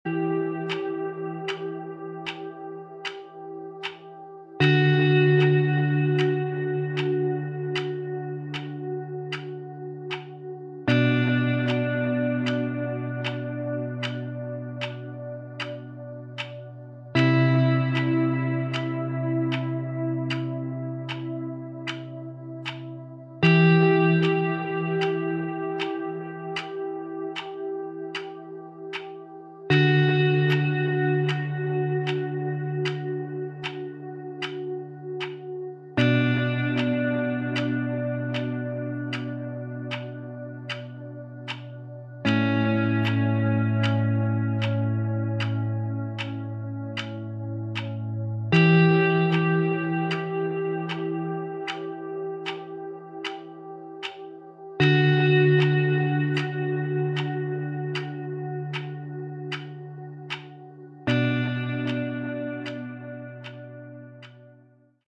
Category: Progressive Rock/Metal
lead vocals
guitars
bass
keyboards
drums